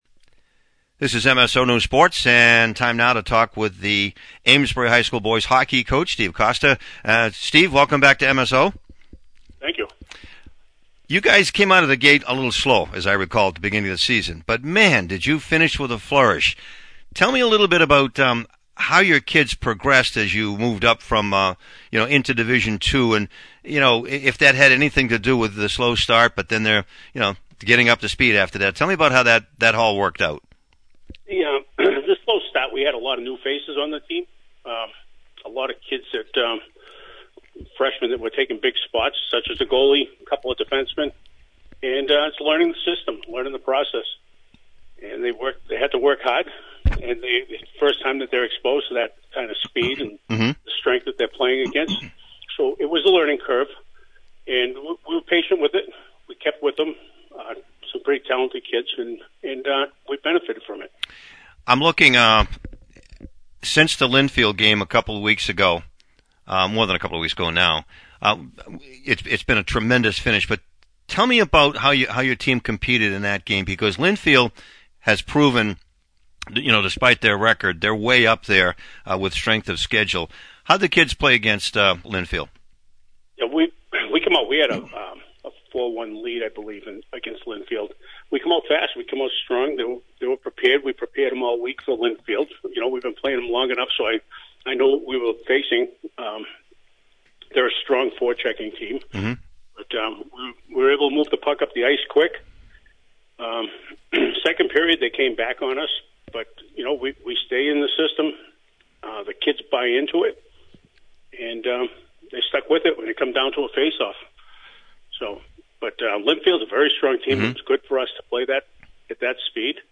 (Audio) Post-game, Pre-game